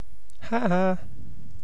Uttal
Alternativa stavningar ha-ha haha Synonymer tee hee ho ho Uttal UK Ordet hittades på dessa språk: engelska Ingen översättning hittades i den valda målspråket.